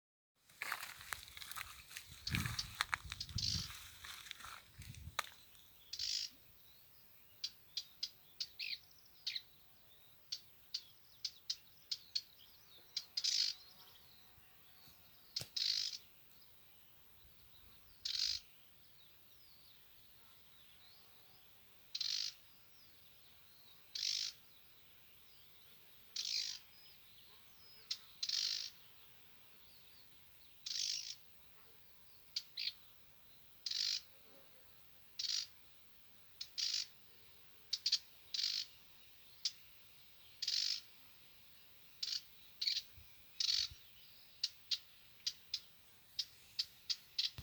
Marsh Warbler, Acrocephalus palustris
Administratīvā teritorijaNīcas novads
StatusAgitated behaviour or anxiety calls from adults